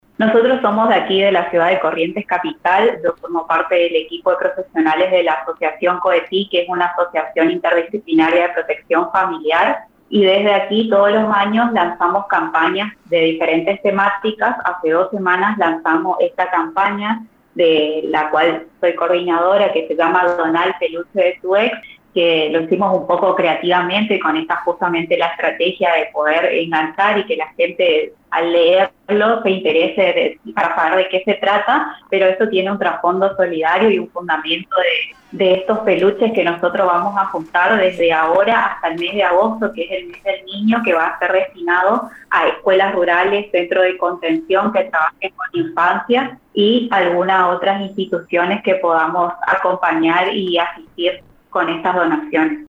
habló con FM Ilusiones en referencia a dicha dijo “todo esto empezó hace dos semanas